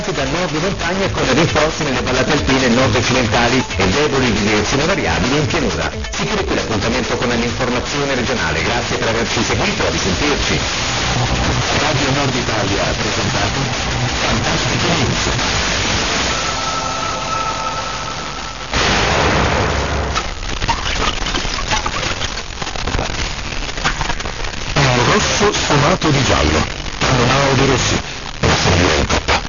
• 89.3 MHz - "Radio Nord Italia presenta, Fantastica News". Strange ID, but I think this is R. Fantastica, Torino with regional news - 1732-